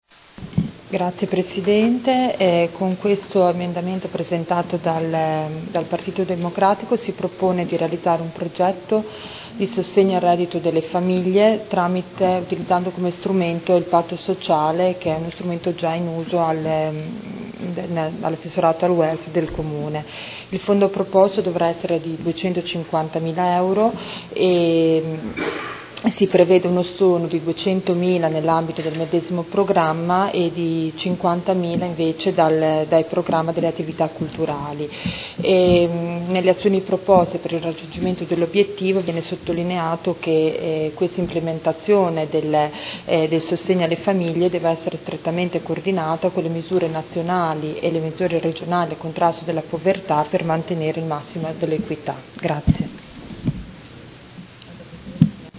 Seduta del 26 gennaio. Bilancio preventivo: emendamento n°9982